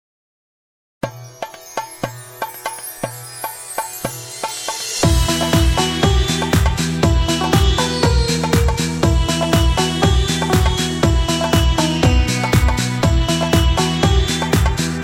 Cultural